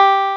CLAVI6 G4.wav